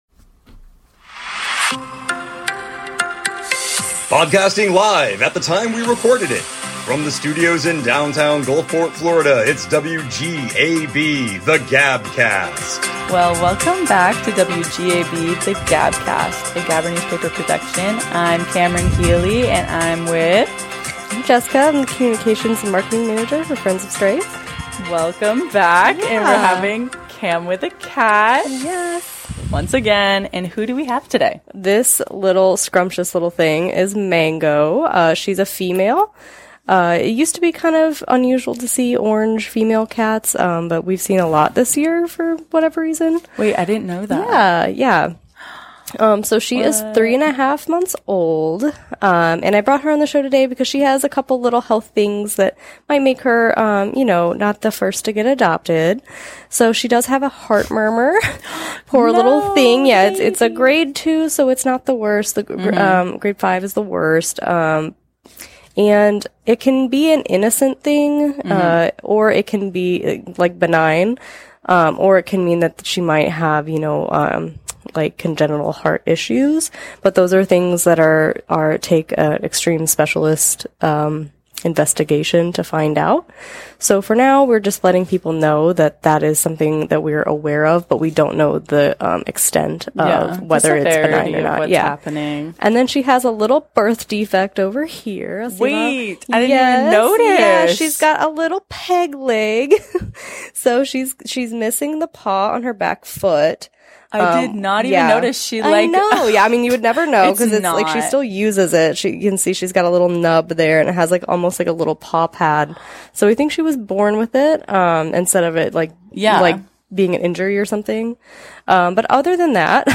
Covering weekly news in Gulfport Florida and South Pinellas. Produced live (when we taped it) in downtown Gulfport, Florida.